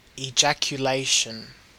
Ääntäminen
Ääntäminen AUS Tuntematon aksentti: IPA : /iˌdʒækjuˈleɪʃən/ Haettu sana löytyi näillä lähdekielillä: englanti Määritelmät Substantiivit The act of throwing or darting out with a sudden force and rapid flight.